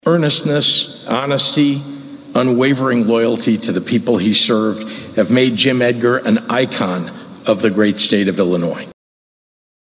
(Springfield, IL)  —  Friends, family and colleagues are saying goodbye to former Illinois Governor Jim Edgar.  Governor Pritzker spoke as Republicans and Democrats gathered Saturday for Edgar’s funeral in Springfield.